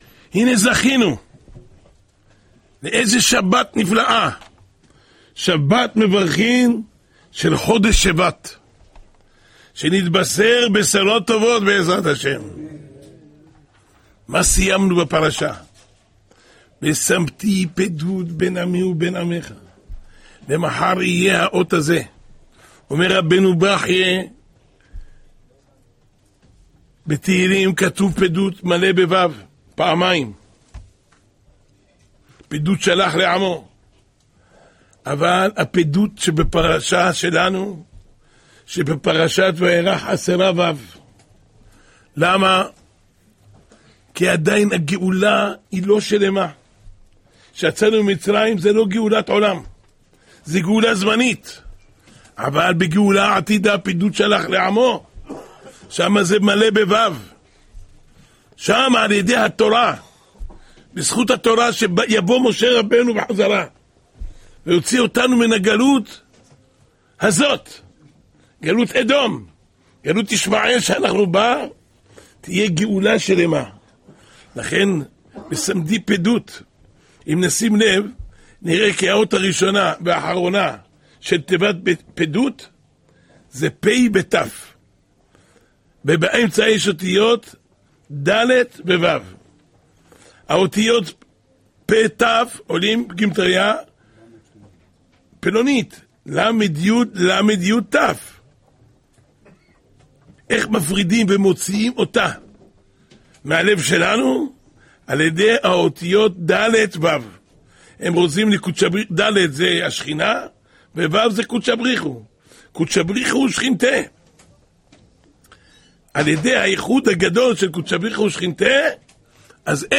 השיעור השבועי